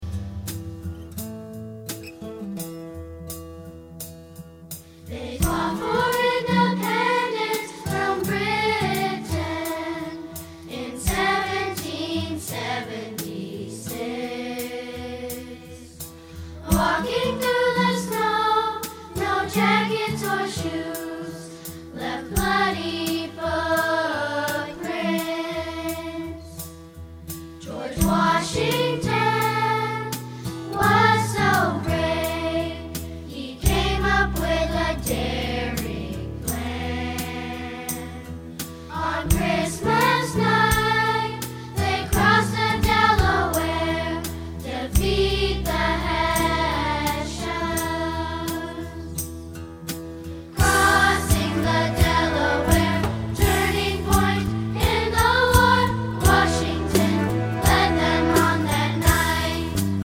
Listen to a sample of this song.